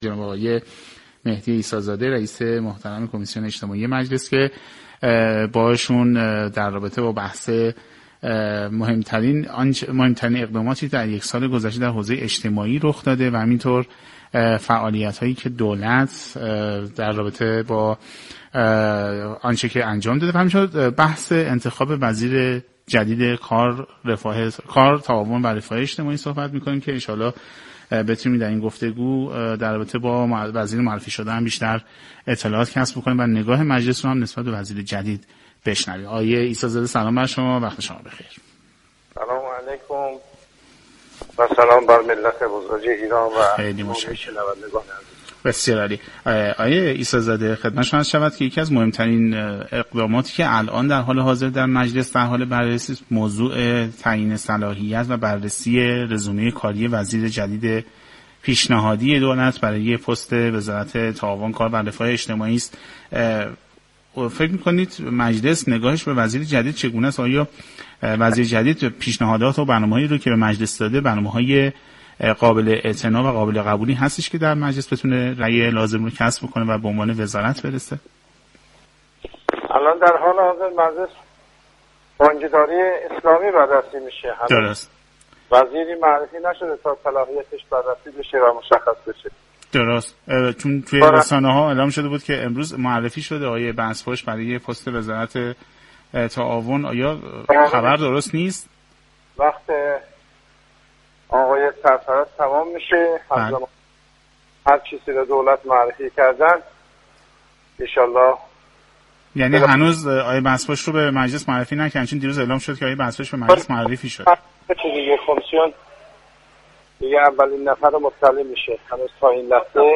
به گزارش پایگاه اطلاع رسانی رادیو تهران، مهدی عیسی زاده رئیس كمیسیون اجتماعی مجلس درباره بررسی تعیین صلاحیت وزیر پیشنهادی رئیس جمهور برای وزارتخانه تعاون، كار و رفاه اجتماعی به برنامه سعادت آباد 7 شهریور گفت: هنوز وزیری به مجلس معرفی نشده تا صلاحیت وی بررسی شود.